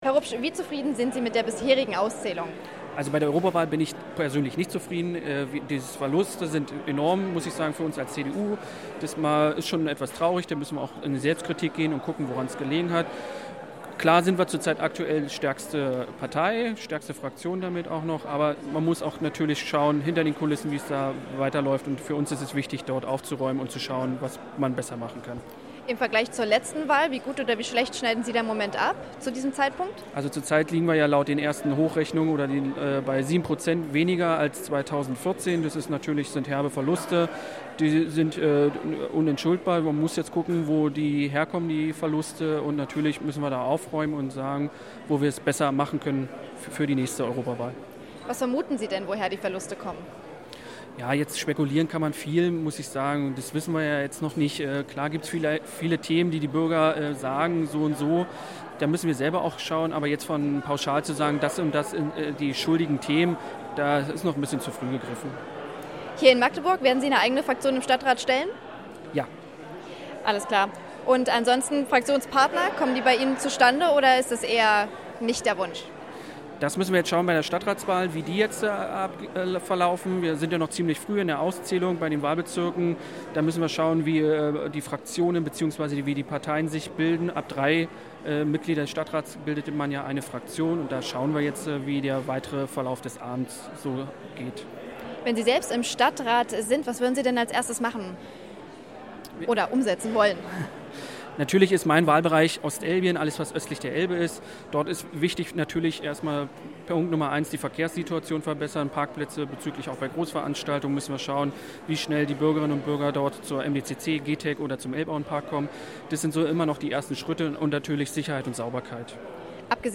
Live-Interview am Wahlabend